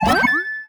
collect_item_08.wav